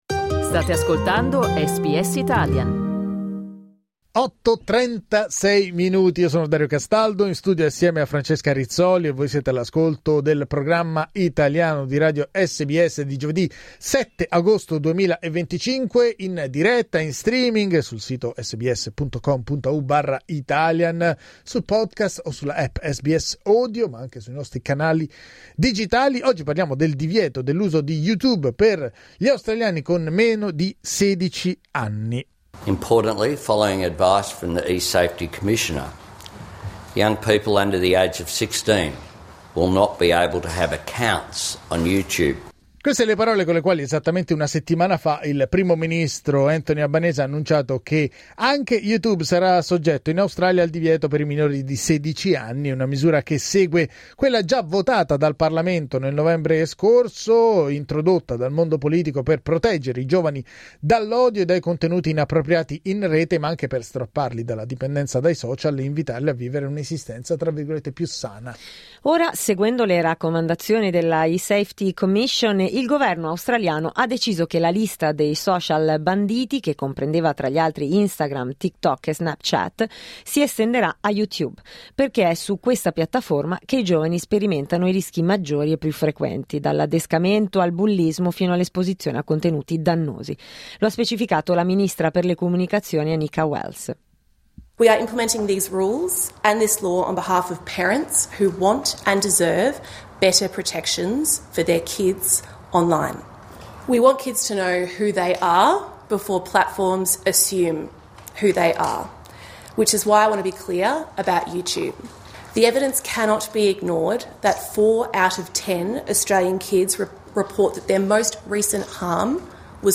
Clicca il tasto 'play' in alto per ascoltare il nostro dibattito Ma la norma, pur animata da intenti protettivi, solleva innanzitutto interrogativi sull’efficacia dei metodi di verifica dell’età.